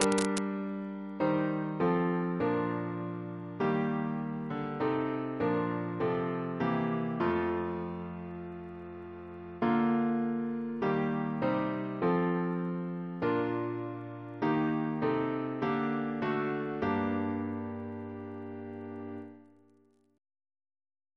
Double chant in G minor Composer: James Nares (1715-1783) Reference psalters: ACP: 67; PP/SNCB: 119; RSCM: 100